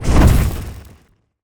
fireball_blast_projectile_spell_06.wav